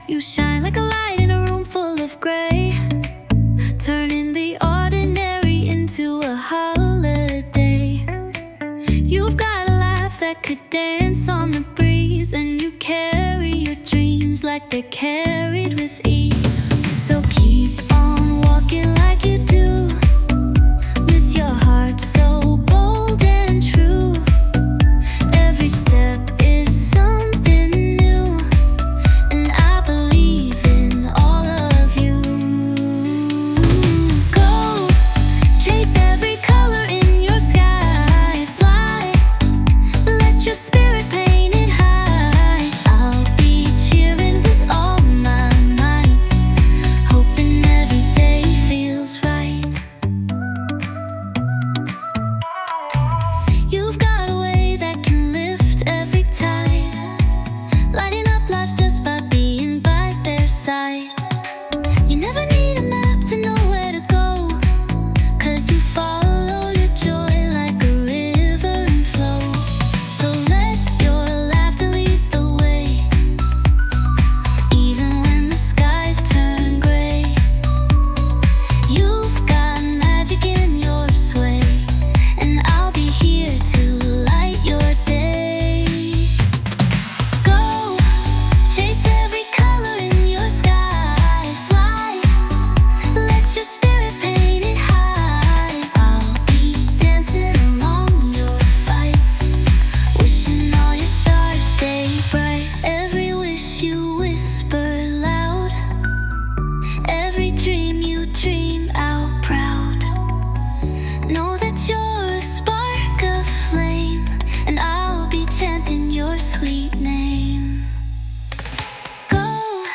洋楽女性ボーカル著作権フリーBGM ボーカル
著作権フリーオリジナルBGMです。
女性ボーカル（洋楽・英語）曲です。
そんなシンプルだけど深い想いを、明るくポップなメロディにのせて歌いました。